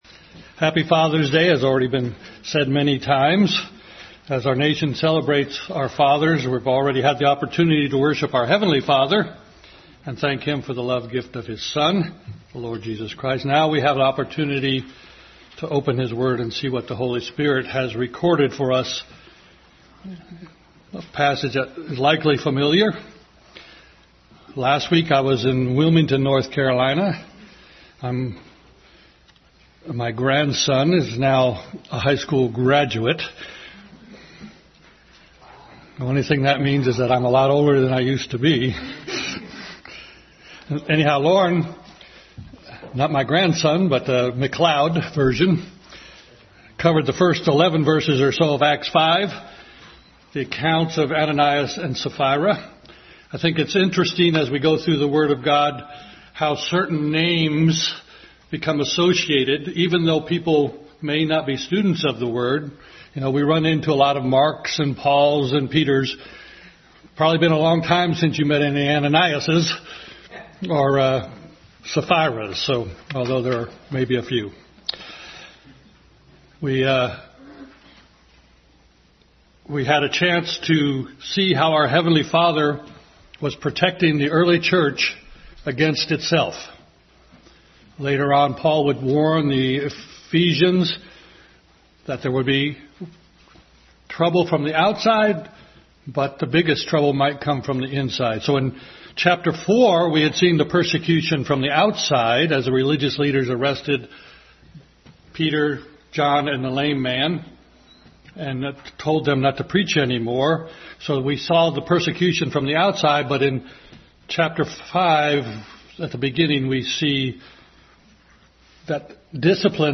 Acts 5 Service Type: Sunday School Bible Text